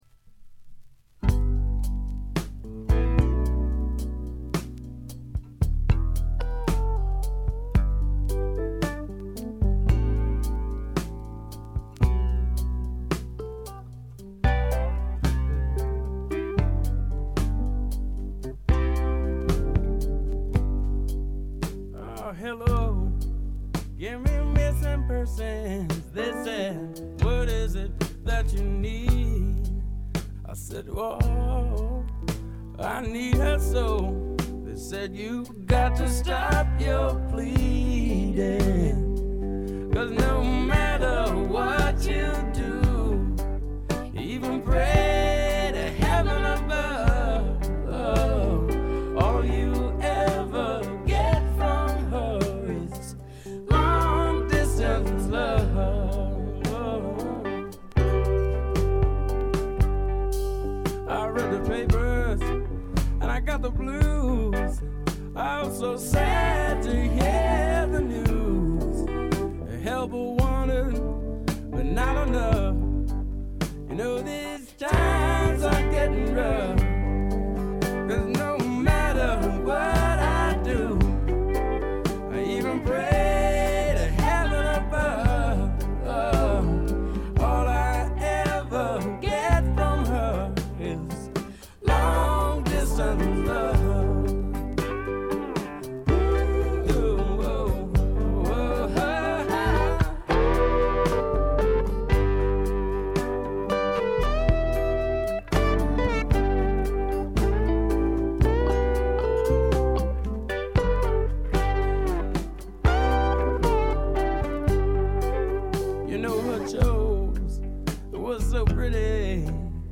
keyboards, synthesizer, vocals